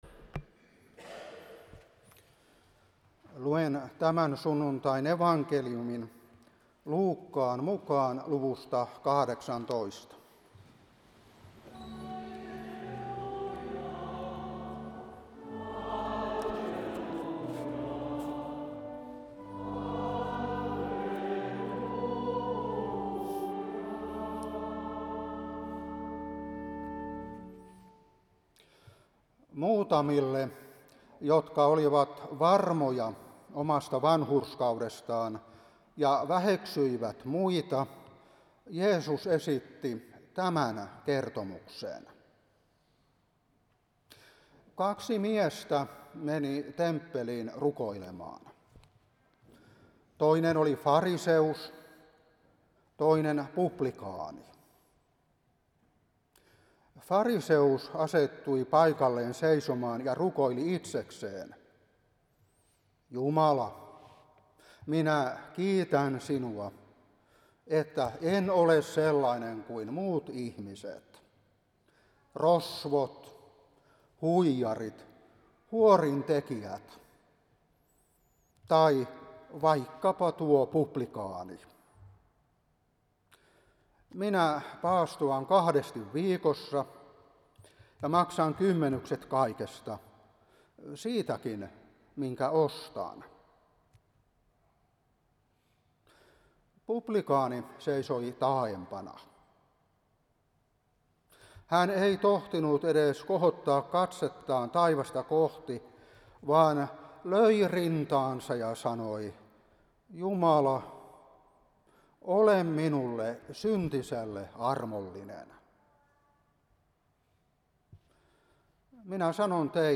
Saarna 2025-8.